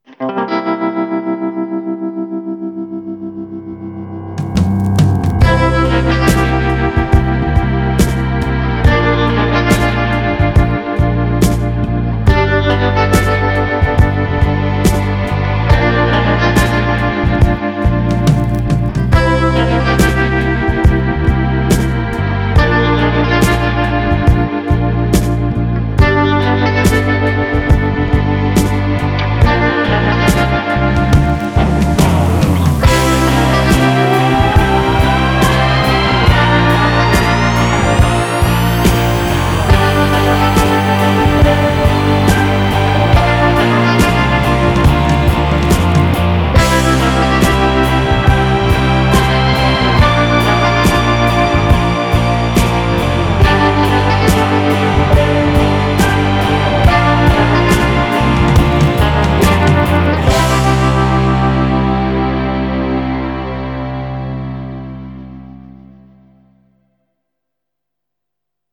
Gut gelaunte Tracks für stressfreie Momente.